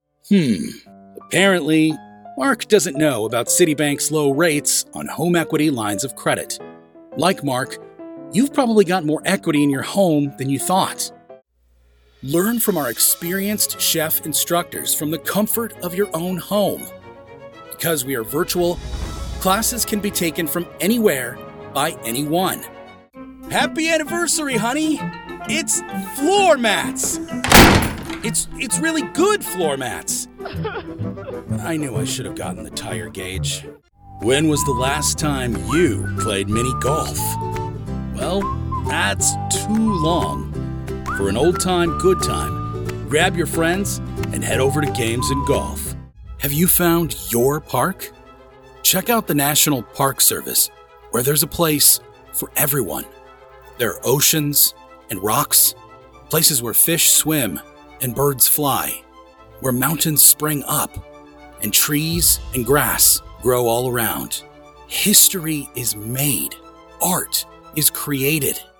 Deep, rich, and warm tones for commercials, narration, and more.
Commercial
Engaging, friendly, and persuasive reads.
• Acoustically Treated Space
demo-commercial.mp3